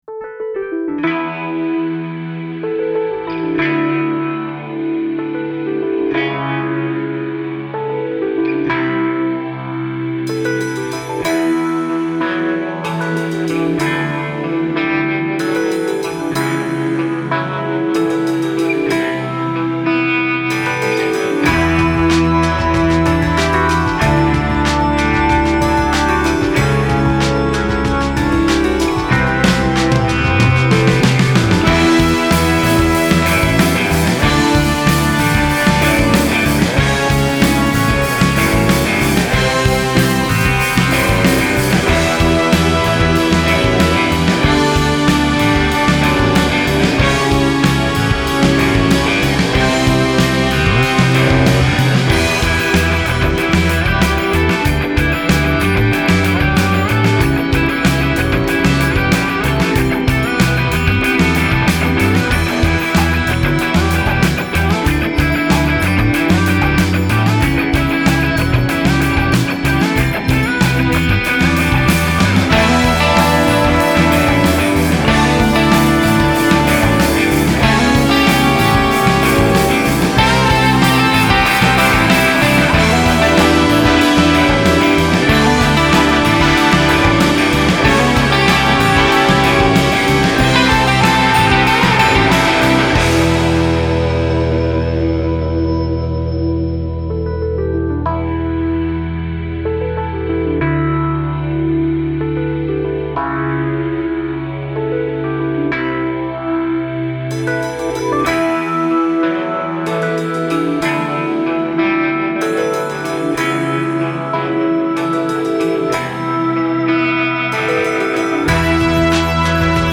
Ist das Indie, ist das Pop?